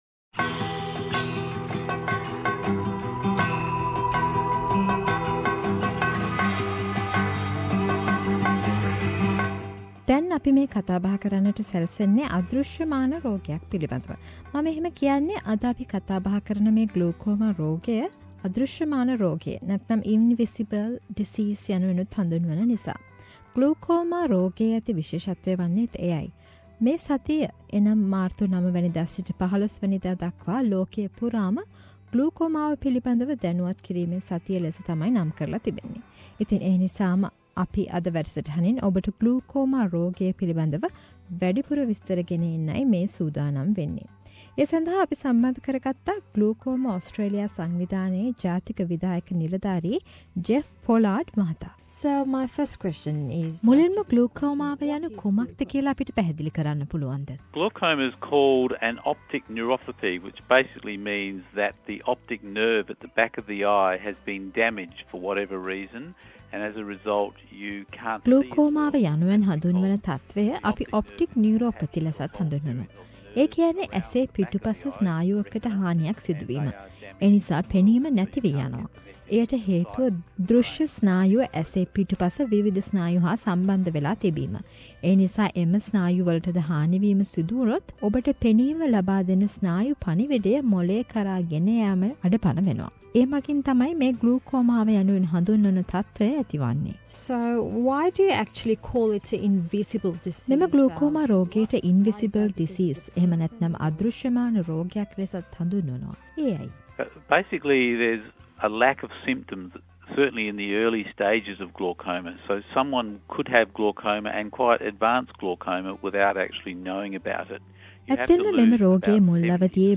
9-15 March is World Glaucoma Week, World Glaucoma Week is held in March each year to increase awareness about glaucoma. SBS Sinhala Radio spoke to Glaucoma Australia to mark this week.